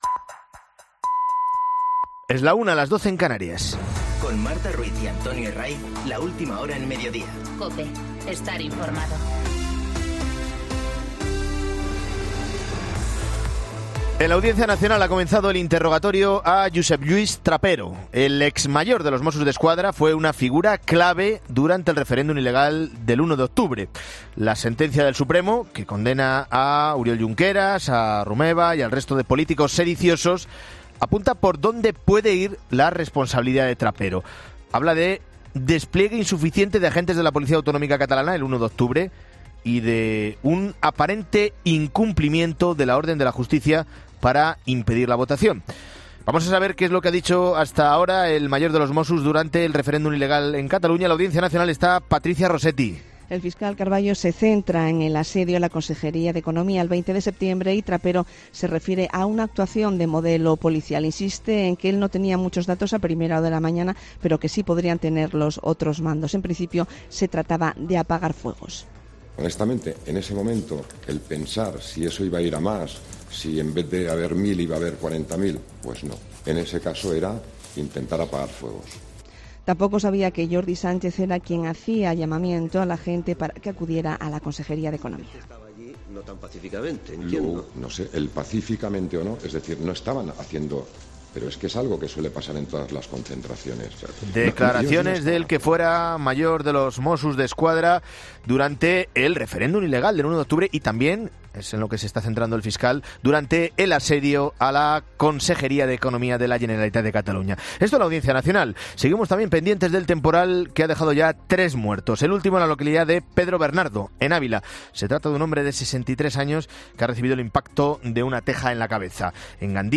Boletín de noticias COPE del 20 de enero de 2020 a las 13.00 horas